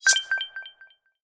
notification-enable.mp3